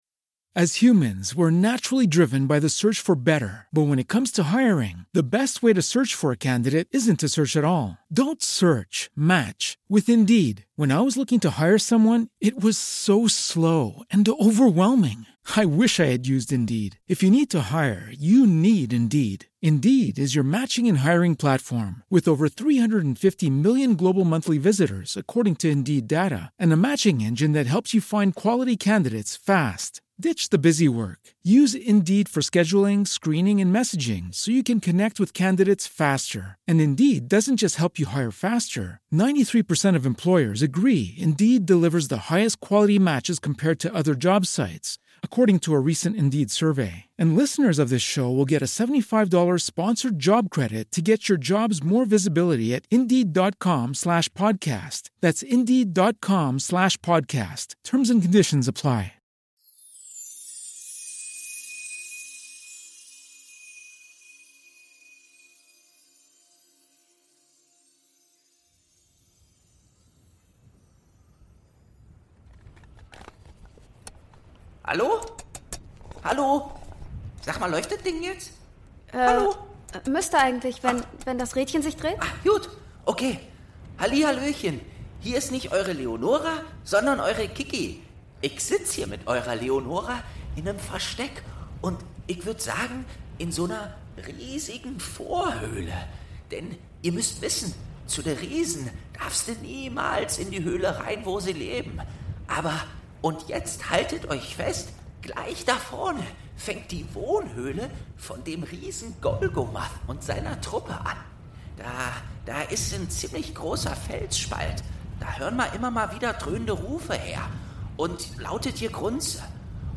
12. Türchen | Keule Riesen Nasenhaar - Eberkopf Adventskalender ~ Geschichten aus dem Eberkopf - Ein Harry Potter Hörspiel-Podcast Podcast